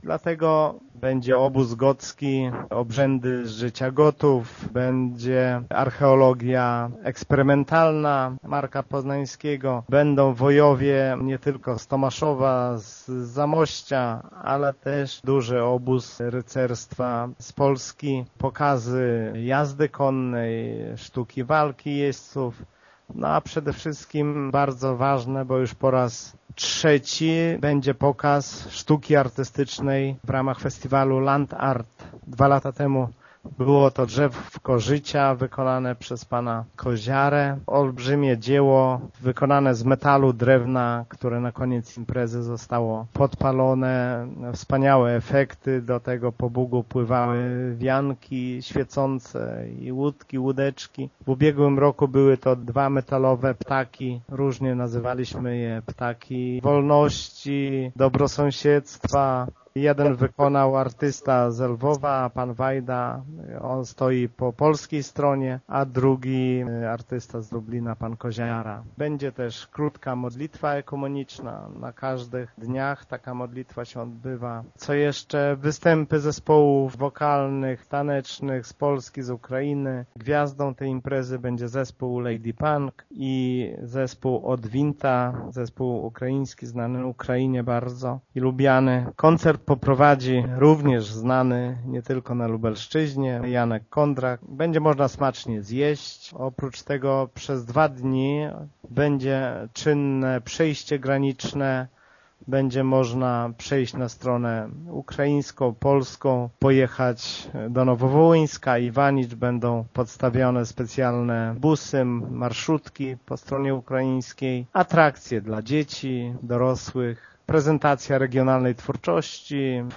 „Drugi powód to towarzyszący tegorocznej imprezie Festiwal Kultury Antycznej „Gotania”, który odbywa się od czerwca w ośmiu miastach i gminach powiatów hrubieszowskiego i tomaszowskiego, w tym w gminie Mircze” – mówi wójt Lech Szopiński: